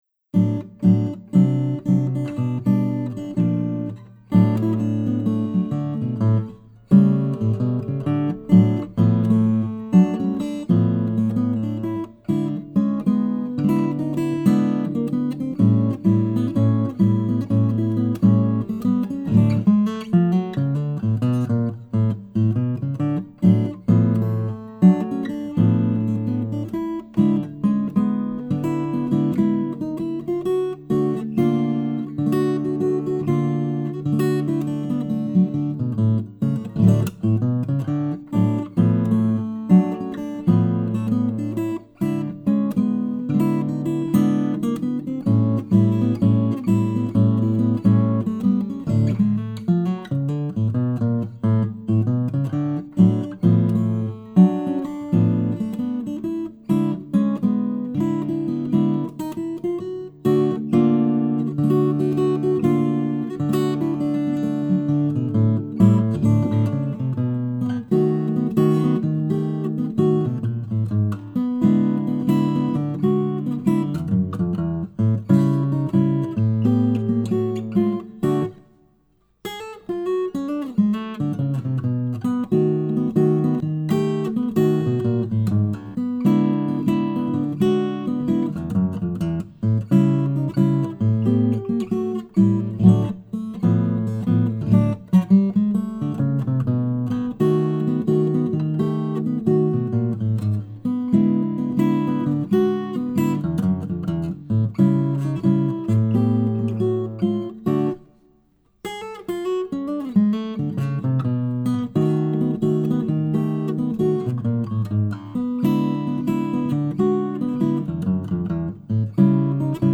DIGITAL SHEET MUSIC - FINGERPICKING SOLO